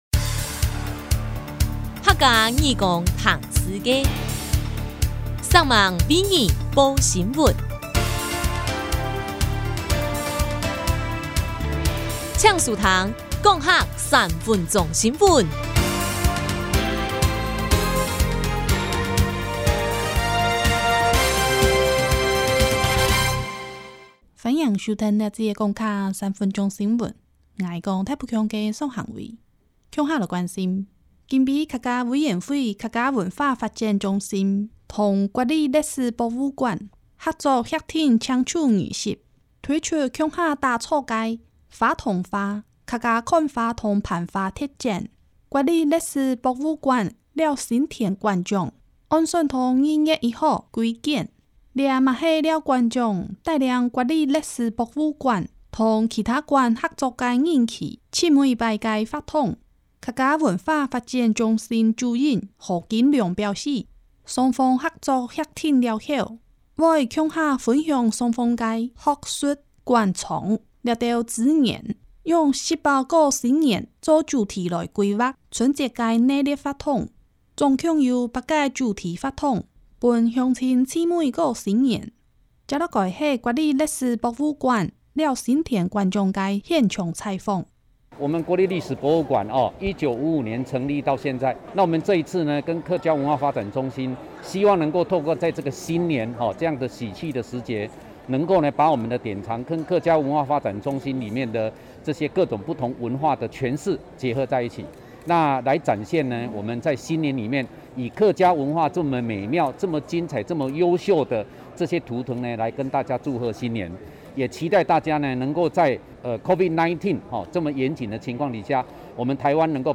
0126客家文化發展中心與國立歷史博物館合作協定簽署儀式-即時廣播新聞.mp3